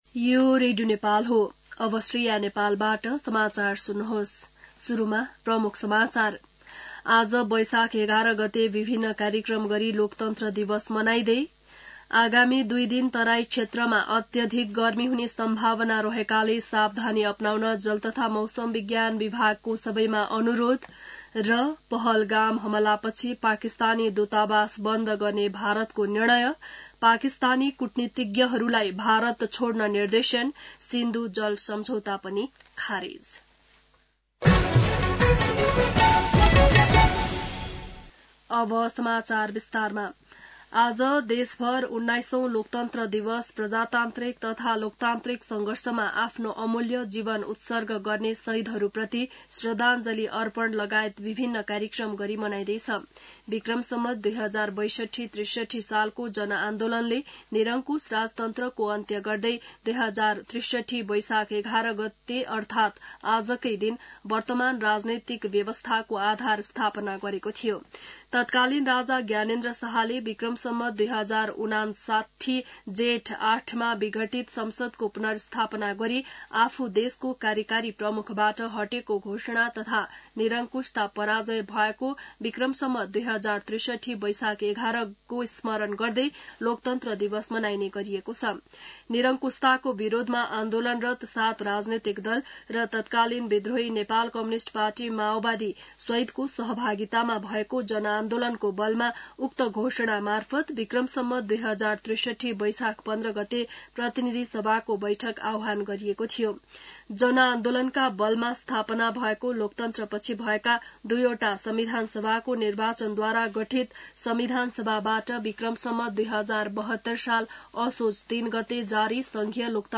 बिहान ९ बजेको नेपाली समाचार : ११ वैशाख , २०८२